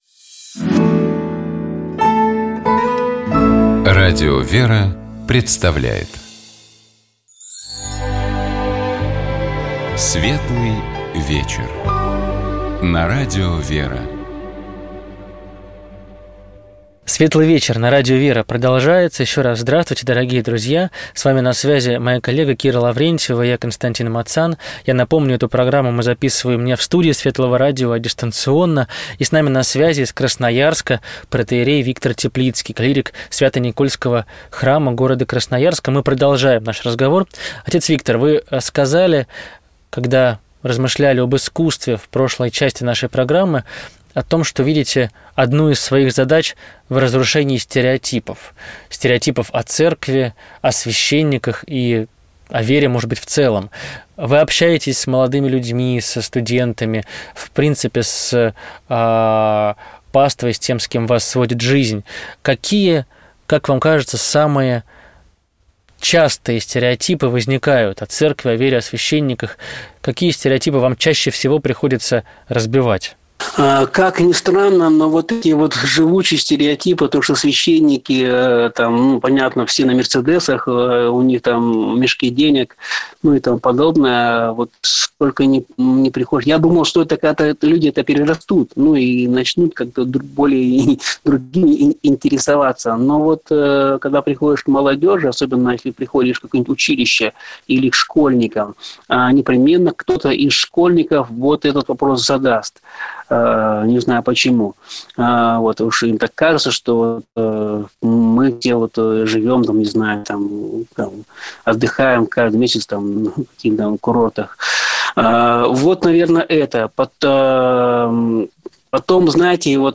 Я напомню, что все программы на радио «Вера» последние недели мы записываем не в студии светлого радио, а дистанционно, мы сохраняем режим самоизоляции, следуем ему, и прочие карантинные меры.